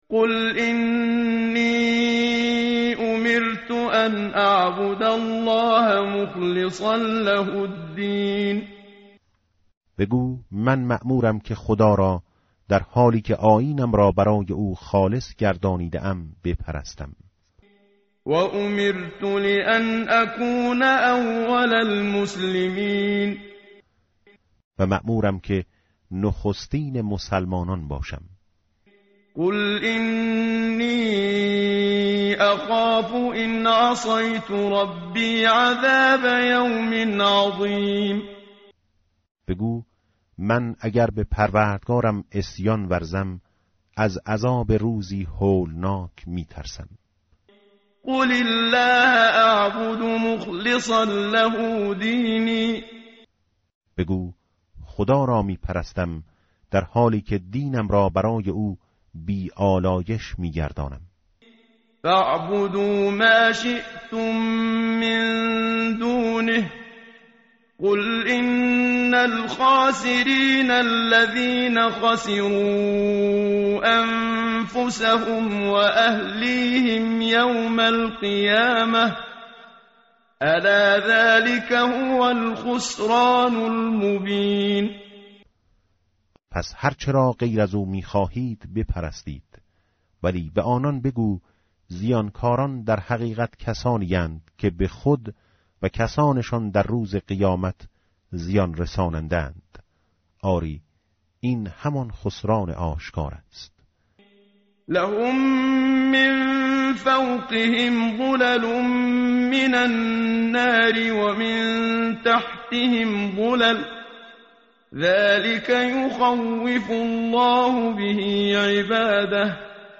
tartil_menshavi va tarjome_Page_460.mp3